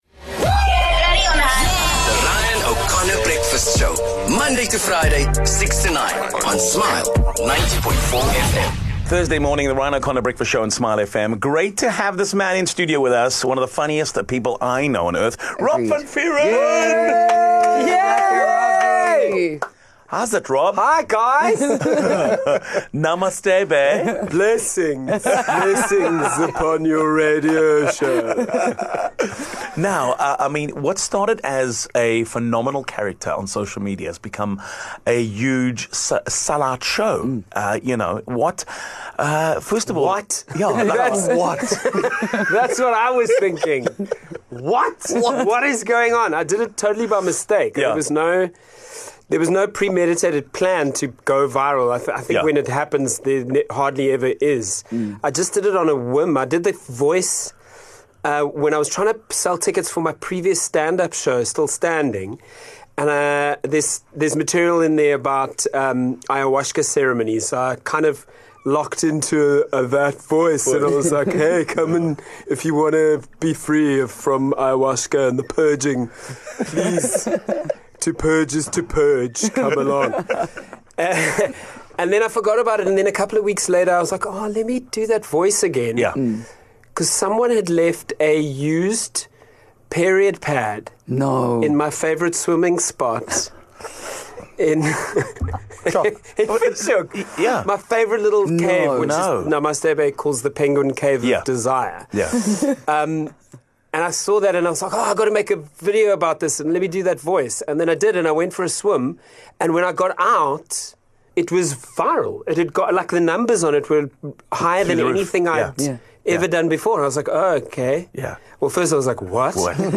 8 Jun Rob van Vuuren: Full interview
The versatile comedian, actor, and voice artist is back with his latest character “Namaste Bae” that's taken the world by storm. We had to get in in studio with us.